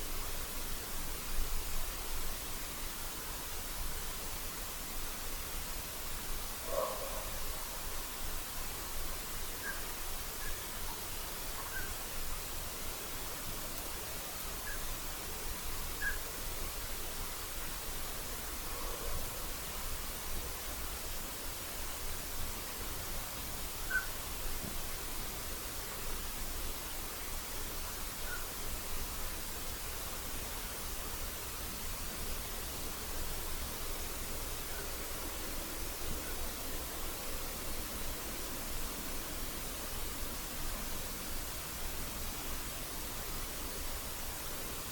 Birds -> Ducks ->
Common Scoter, Melanitta nigra
StatusPasses over (transit)
Notesnakts ieraksts